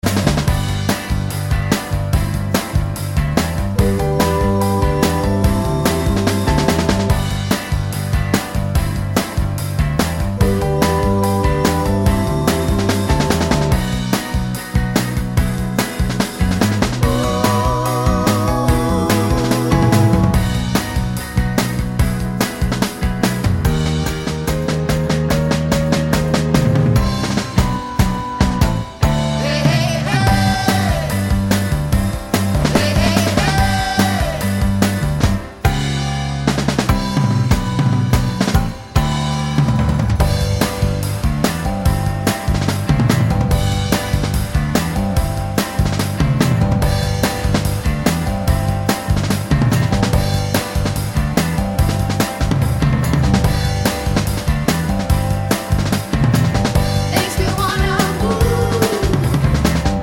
no Backing Vocals Soul / Motown 3:01 Buy £1.50